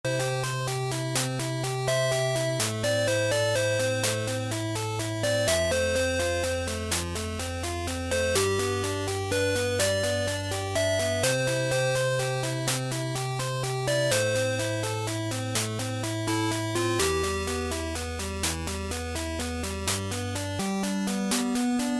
пальма, море, закат и чайки